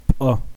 пӏэ like pan but ejective [1]
^ a b c d e f g h i j k l Ejective consonants, which do not occur in English, are voiceless consonants that are pronounced with pressure from the throat rather than the lungs.